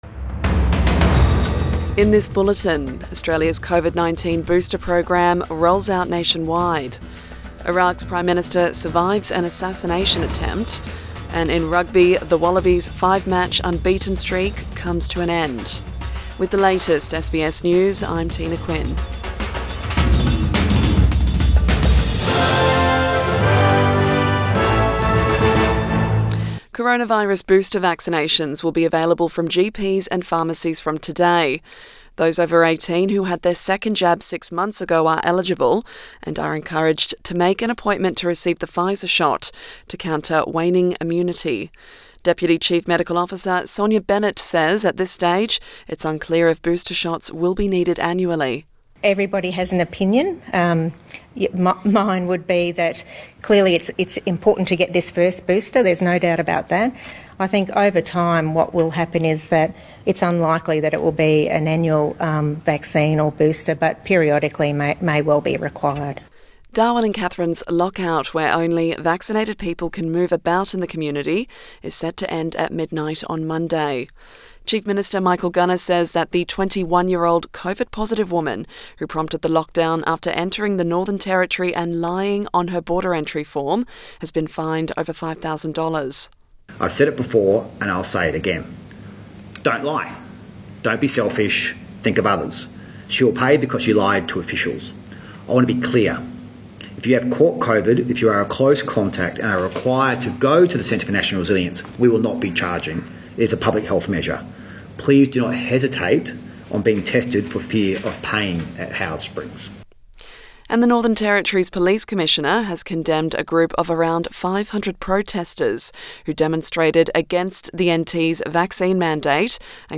AM bulletin 8 November 2021